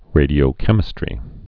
(rādē-ō-kĕmĭ-strē)